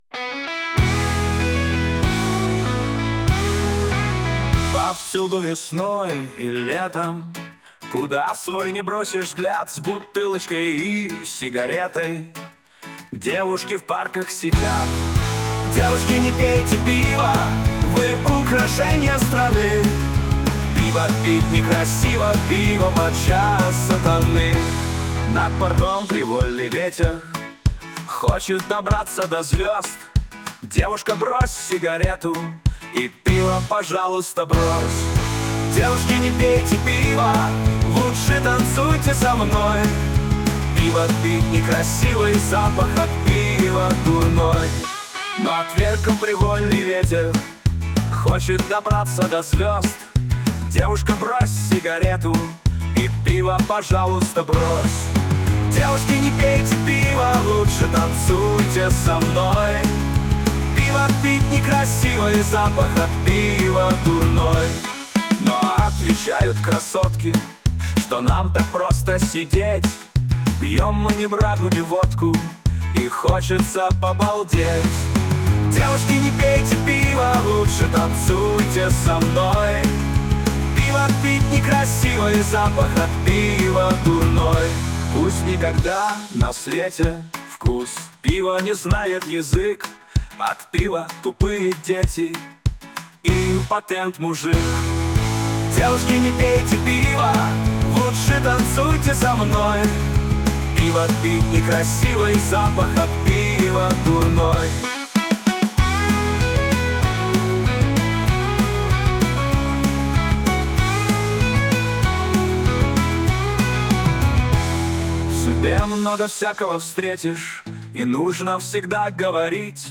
Сегодня с помощью Искусственного интеллекта что-то получилось.